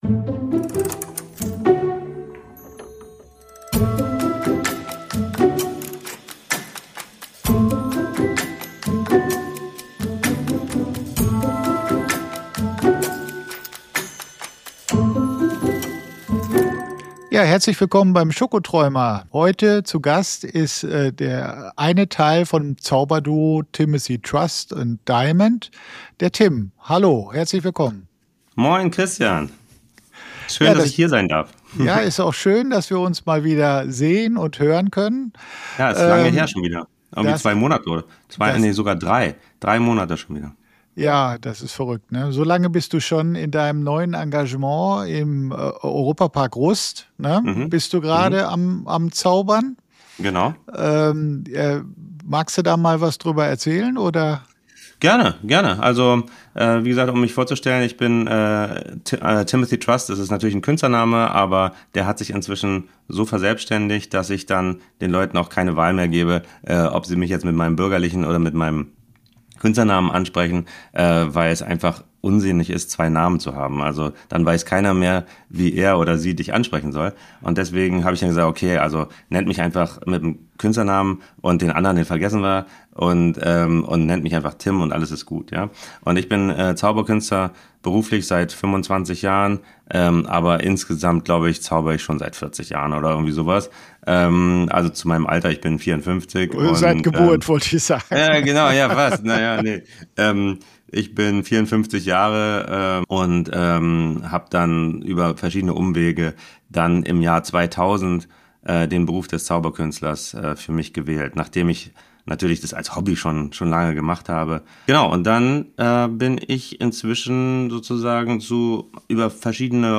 Ein Gespräch voller Inspiration, Charme und einem Augenzwinkern für alle, die glauben, dass Pralinen mehr können als nur gut schmecken Mehr